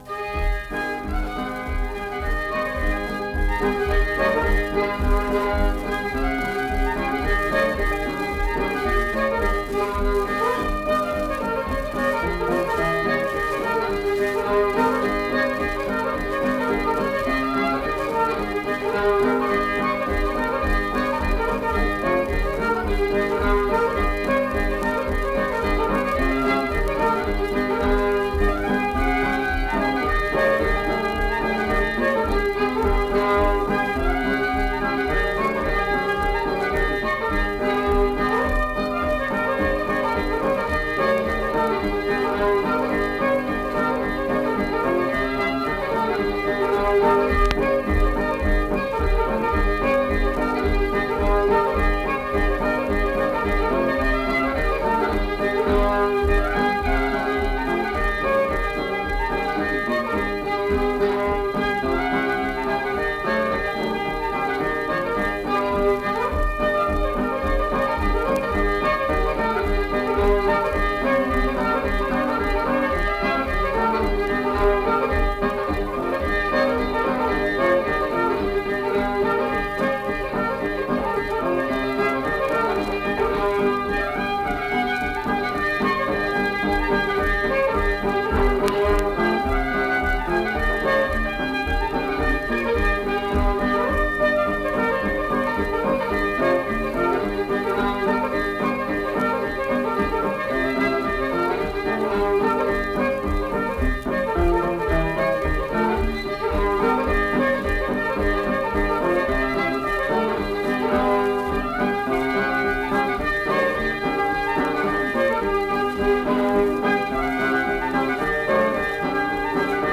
Accompanied guitar and unaccompanied fiddle music performance
Instrumental Music
Guitar, Fiddle